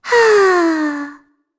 Shea-Vox_Casting1.wav